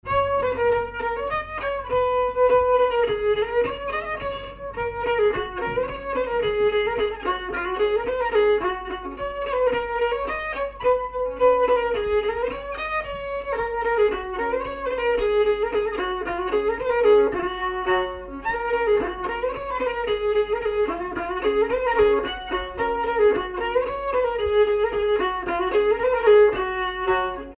Marche
danse : marche
circonstance : bal, dancerie
Pièce musicale inédite